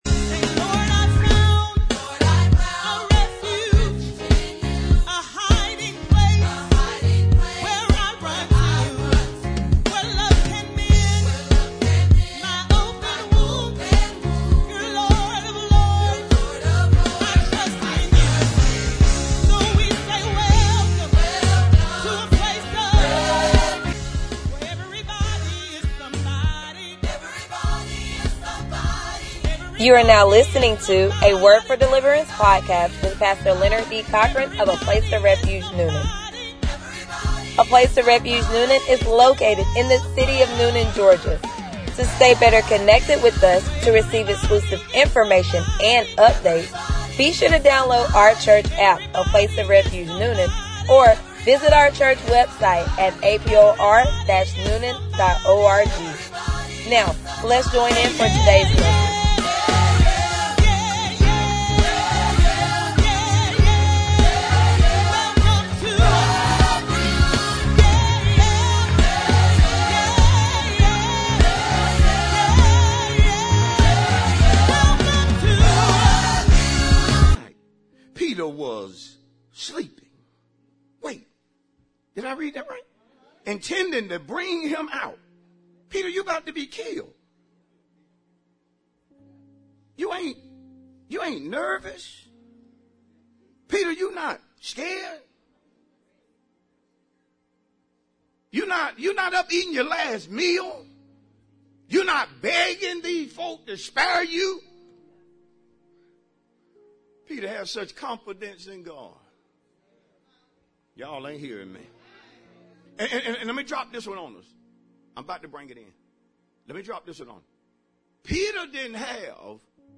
Sermons | A Place Of Refuge Newnan